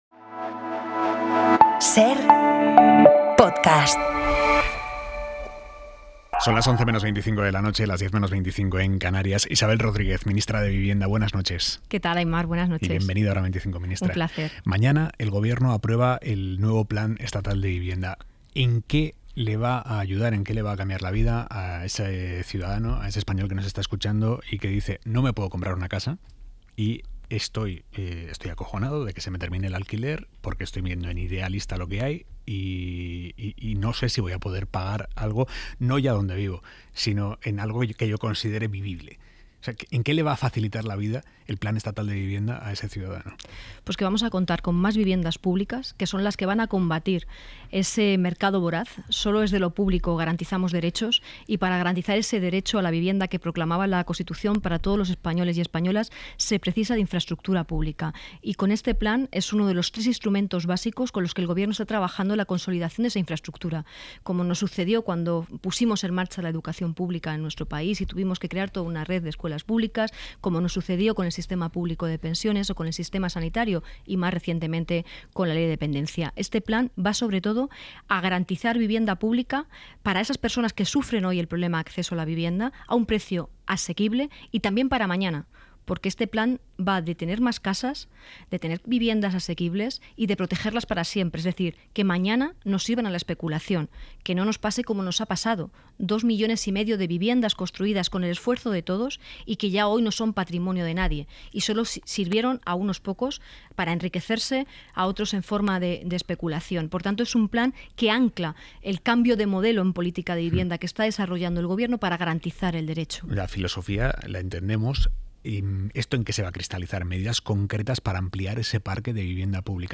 Aimar Bretos entrevista a Isabel Rodríguez, Ministra de Vivienda y Secretaria de Vivienda y Agenda Urbana del PSOE.